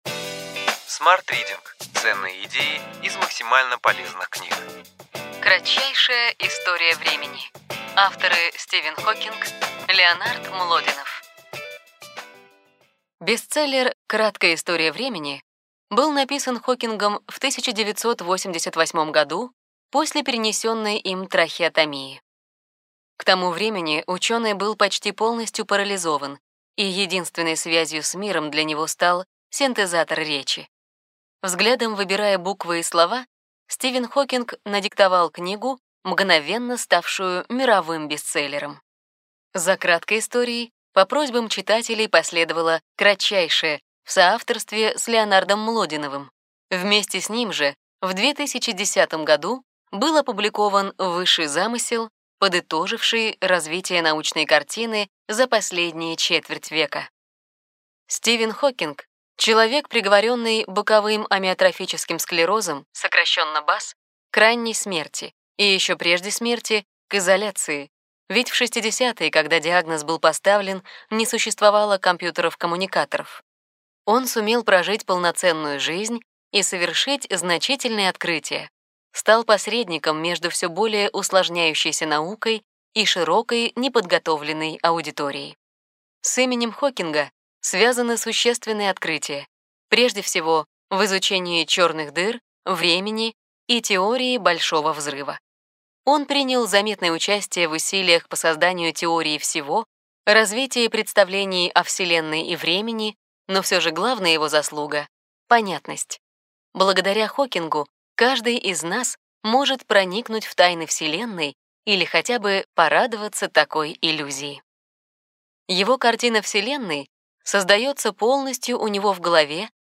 Аудиокнига Ключевые идеи книги: Кратчайшая история времени. Высший замысел. Стивен Хокинг, Леонард Млодинов | Библиотека аудиокниг